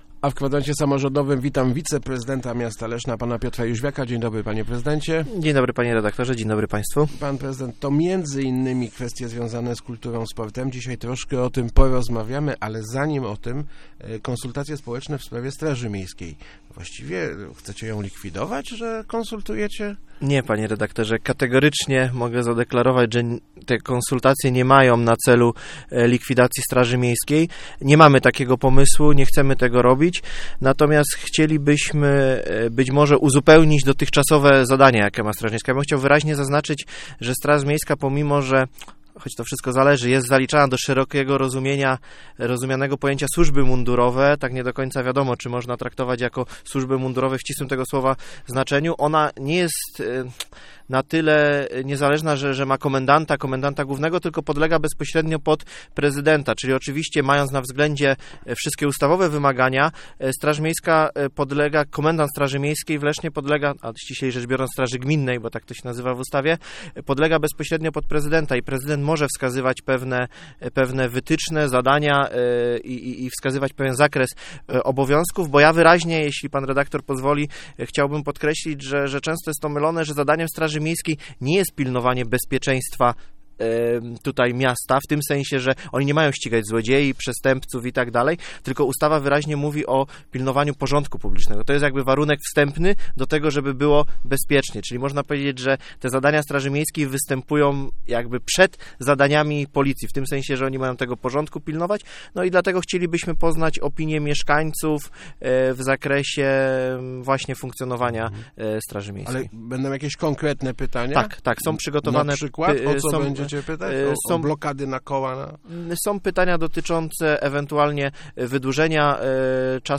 Gościem Kwadransa był wiceprezydent Piotr Jóźwiak.